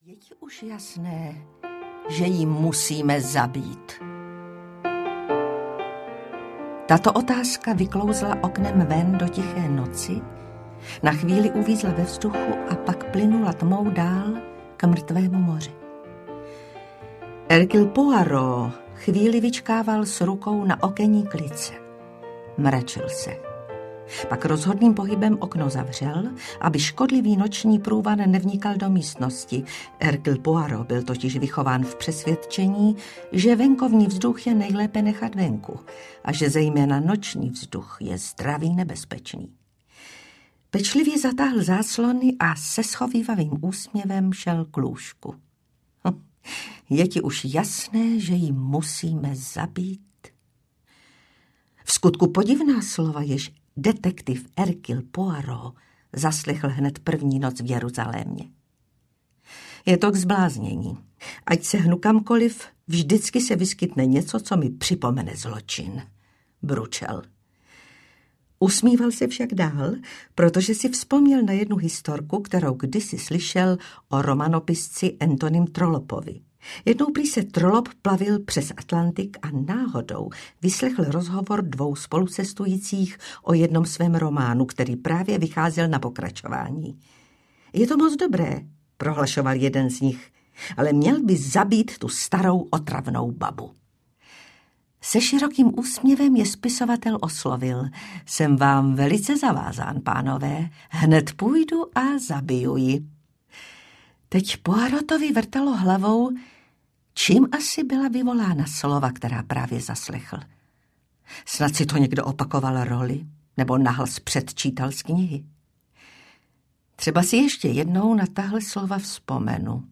Schůzka se smrtí audiokniha
Ukázka z knihy